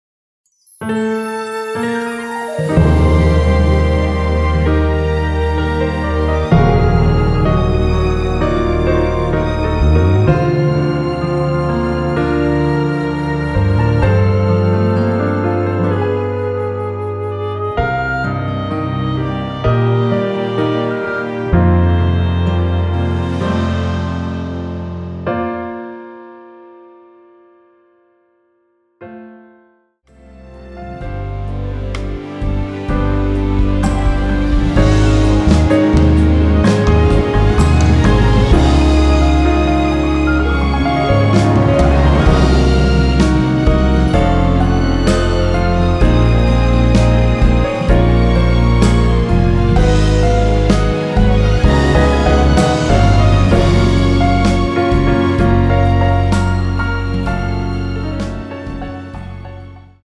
(-2) 내린 MR 입니다.(미리듣기 참조)
Bb
앞부분30초, 뒷부분30초씩 편집해서 올려 드리고 있습니다.